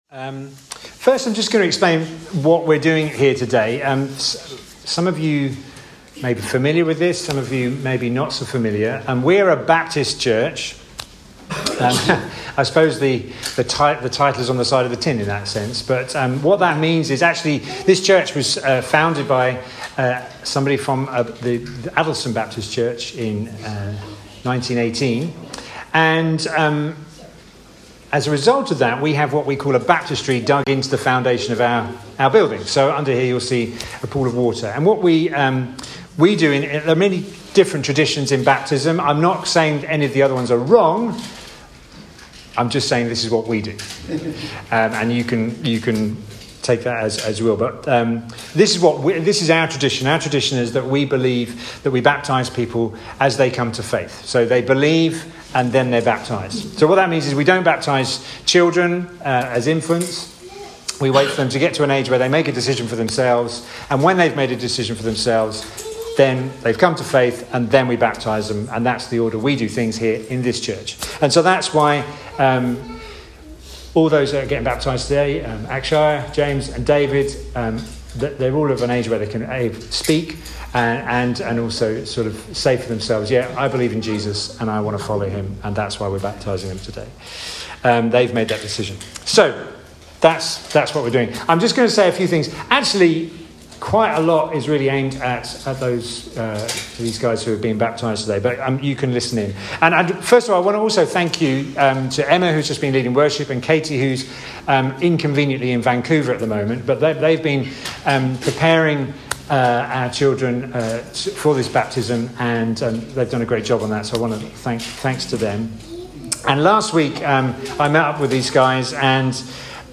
Baptism Sunday – 9th July 2023
Weekly message from The King’s Church.